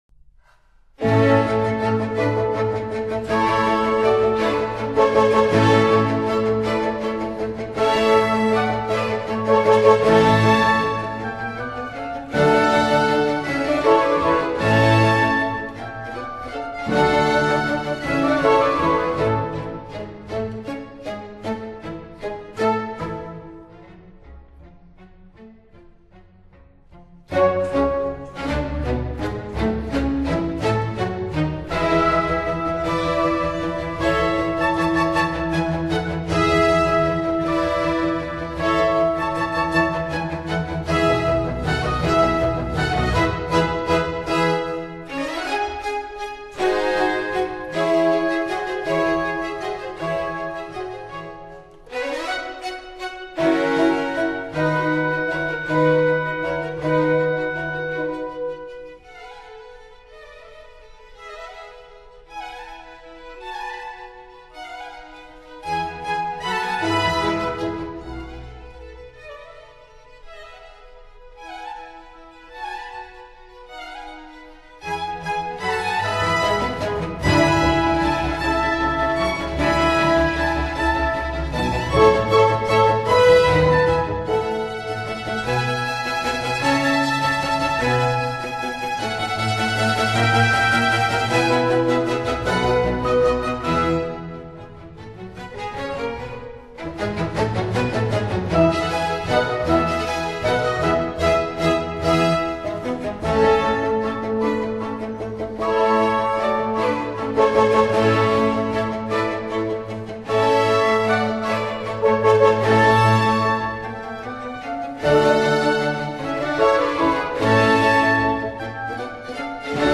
Allegro e vivo